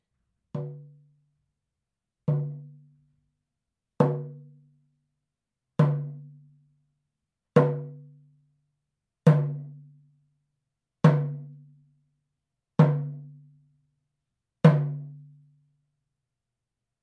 ネイティブ アメリカン（インディアン）ドラム NATIVE AMERICAN (INDIAN) DRUM 12インチ（deer 鹿）
ネイティブアメリカン インディアン ドラムの音を聴く
乾いた張り気味の音です